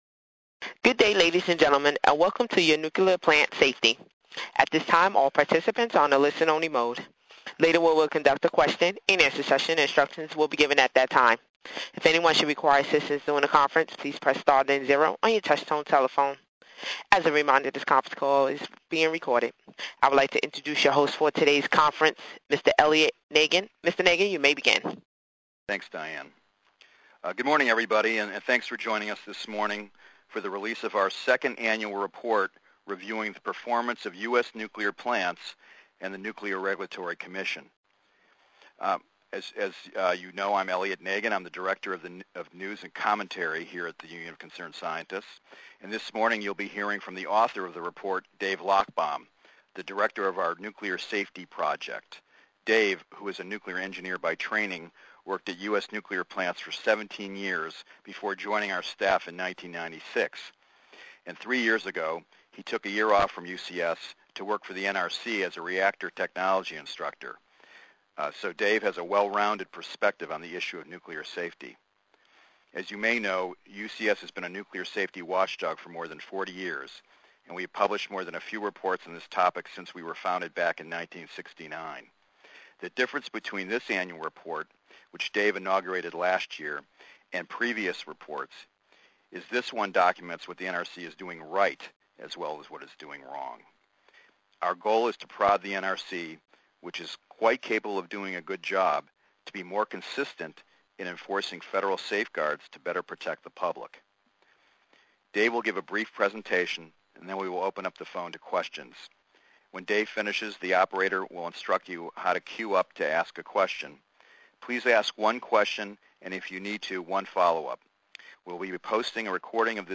NRC-safety-2011-press-conference.mp3